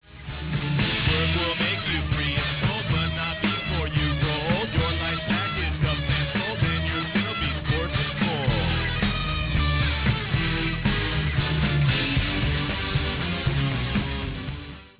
Weird, Experimental, Punk, College/Indie/Lo-Fi, Hard Rock